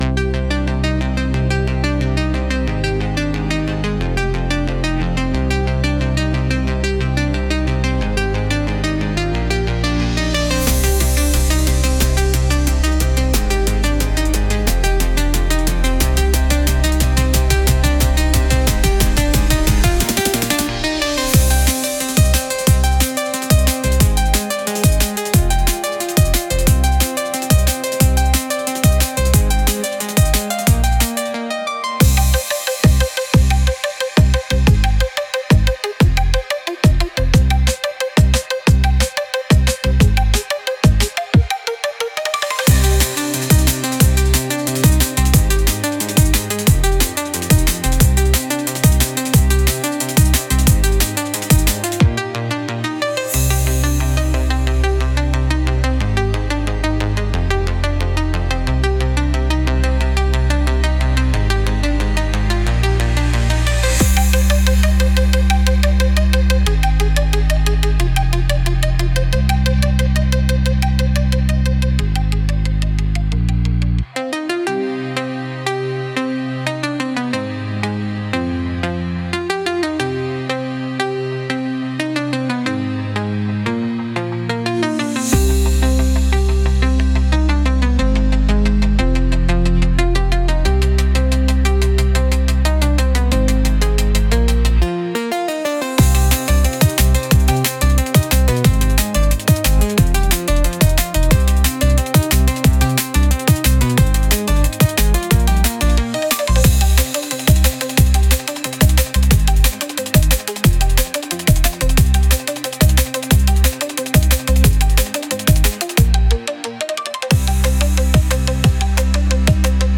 Genre: Electronic Mood: Upbeat Editor's Choice